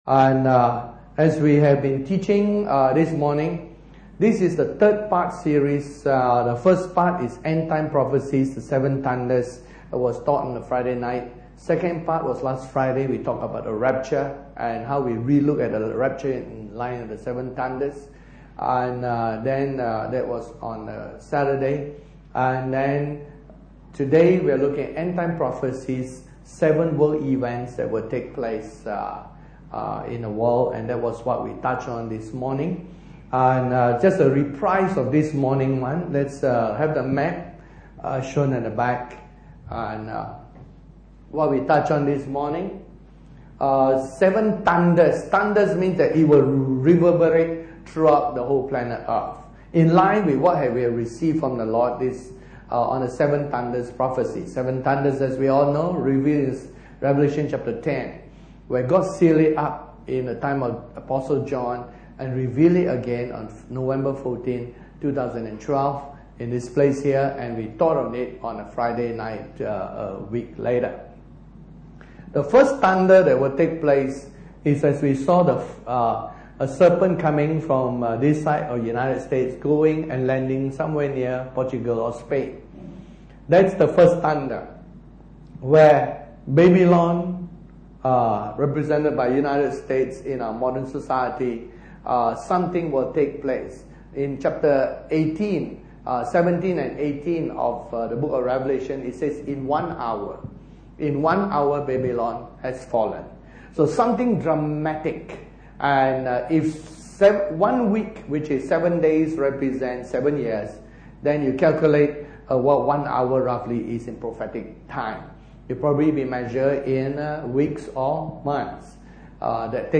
Series: EndTime Prophecies Tagged with Sunday Service